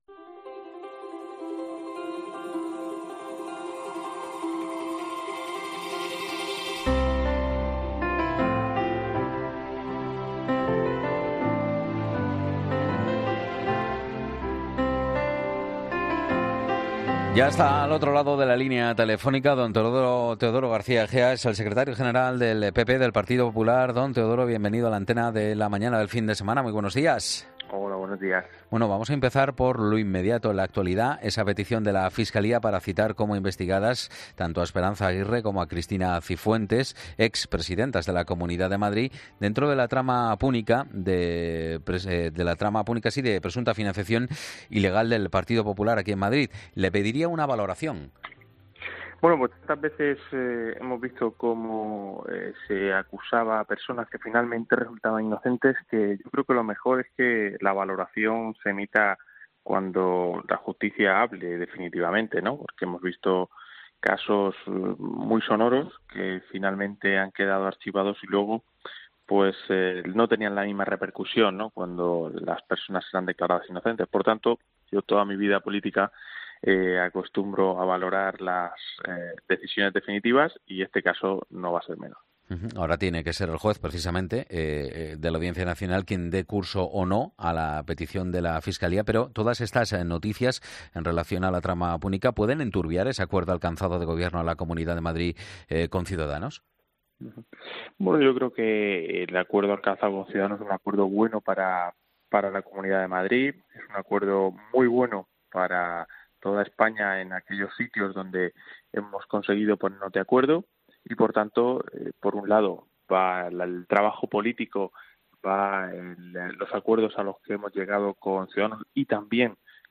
En una entrevista este sábado en el programa "La mañana Fin de Semana" en COPE, el 'número 2' del partido sí ha recordado que otros dirigentes del PP que fueron investigados acabaron exonerados de cualquier responsabilidad.